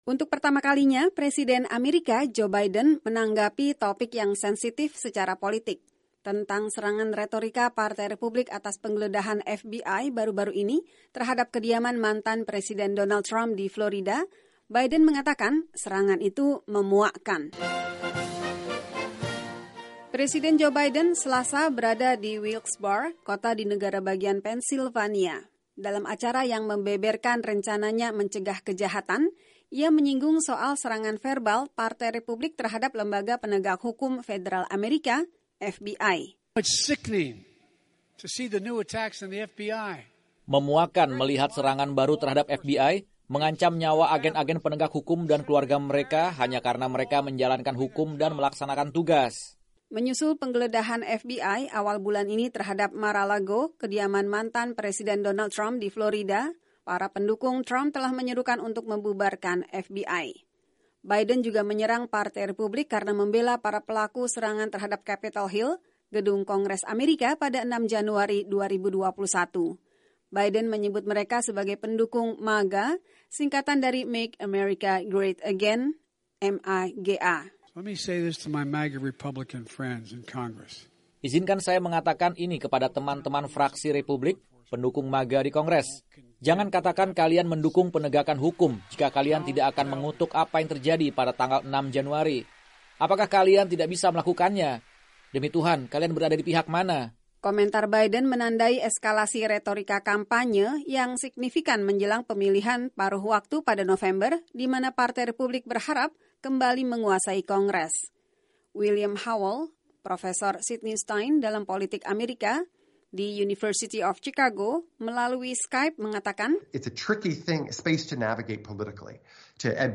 Presiden AS Joe Biden menyampaikan pidato tentang kejahatan terkait senjata api dan "Rencana Amerika yang Lebih Aman" (Safer America Plan) di Wilkes-Barre, kota di negara bagian Pennsylvania, Selasa (30/8).
Wilkes-Barre, Pennsylvania (VOA) —